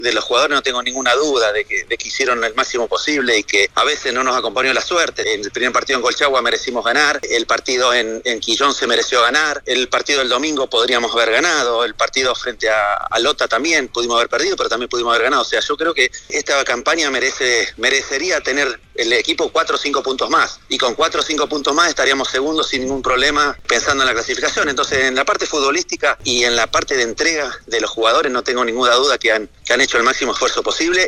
En entrevista con la Onda Deportiva de Radio Sago, Ricardo Lunari entregó detalles de los motivos de su renuncia a la banca de Deportes Provincial Osorno, tras poco más de un año al mando del cuadro taurino.